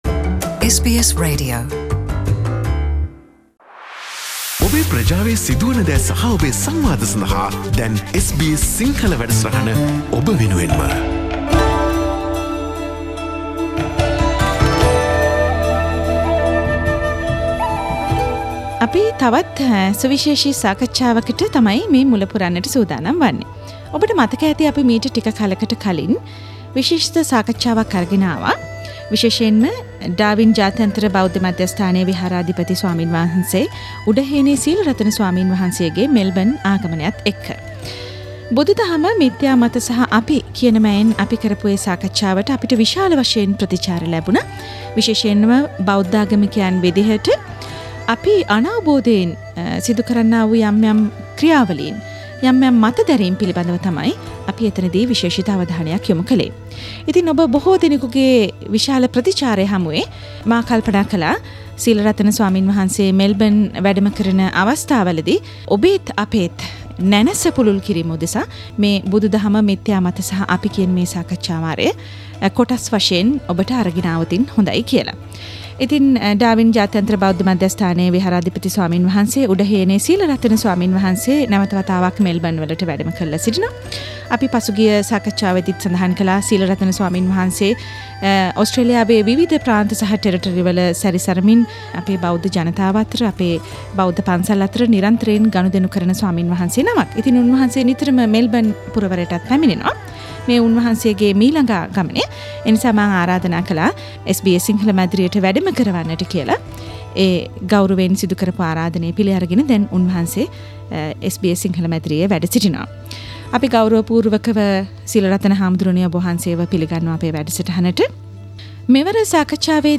SBS Melbourne studios